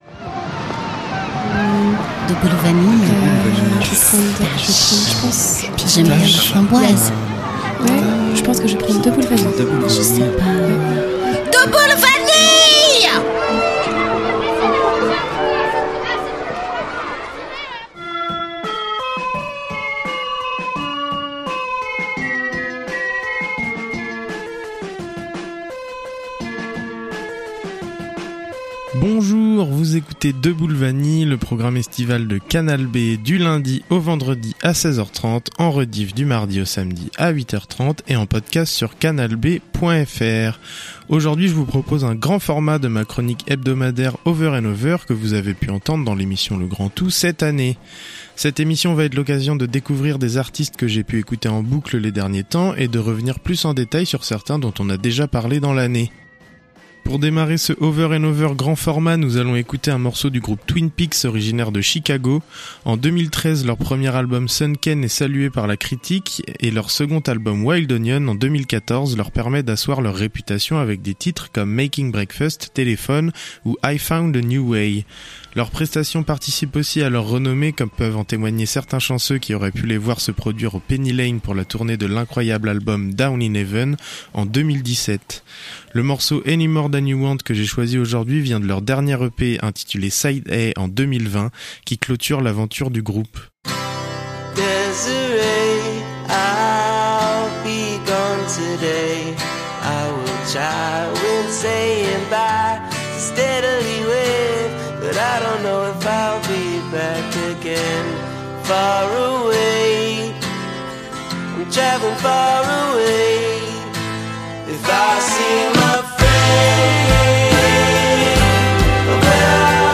Deux Boules Vanille vous accompagne en été sur Canal B avec de la musique, des invités, des reportages, et des surprises !